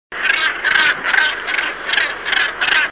Art: Havsule (Morus bassanus)